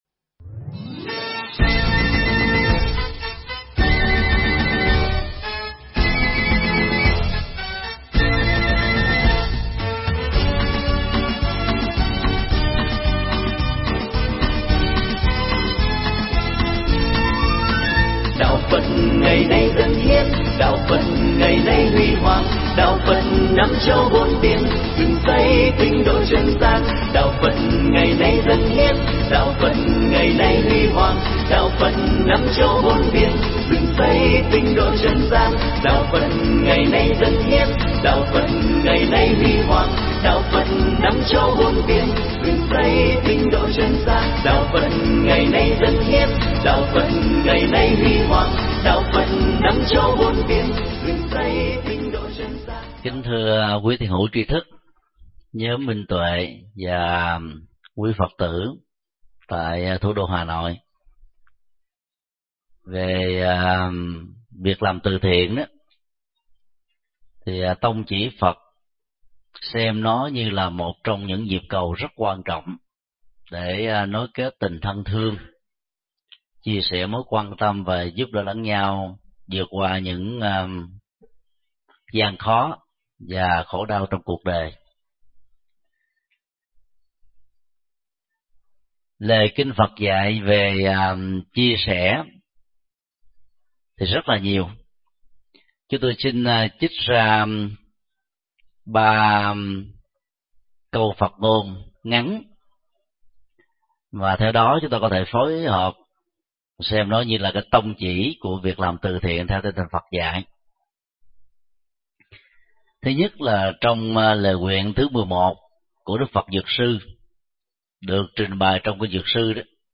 Pháp thoại Giá trị từ thiện và kinh nghiệm từ thiện
giảng tại Hanoi Tourist Building